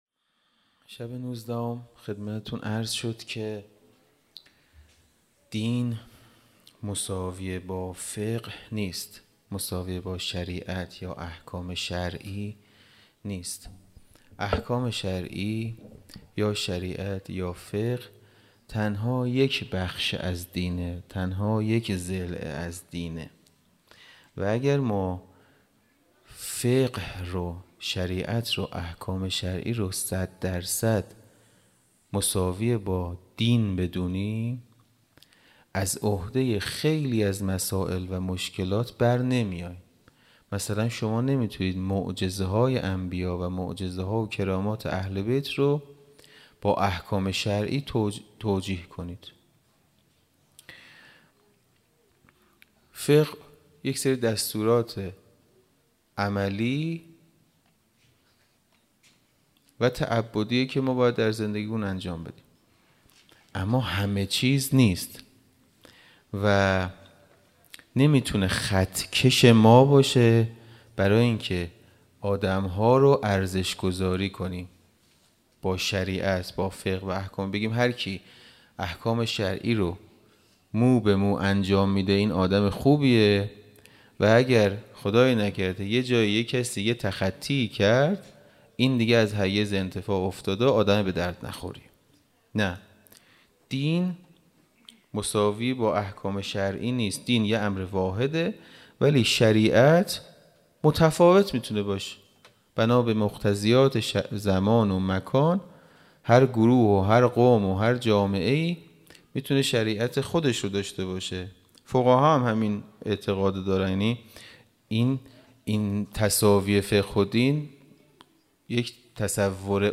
خیمه گاه - حسینیه کربلا - شب 21-سخنرانی